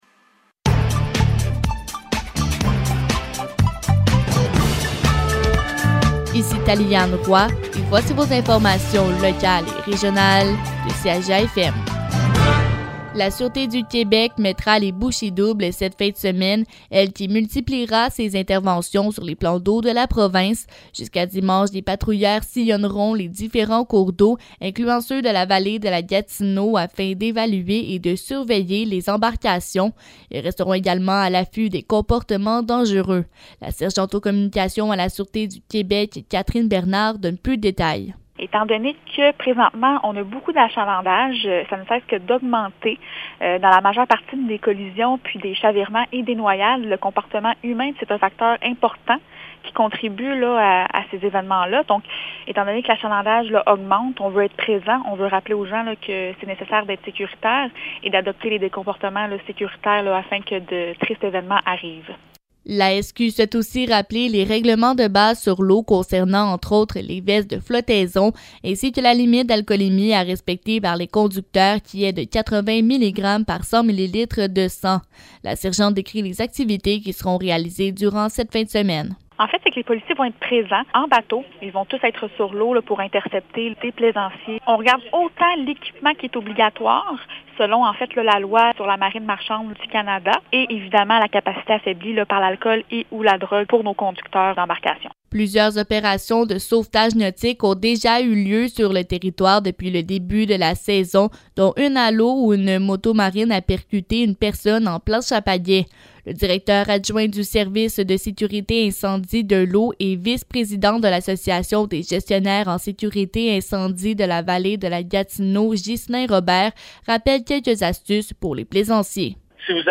Nouvelles locales - 29 juillet 2022 - 12 h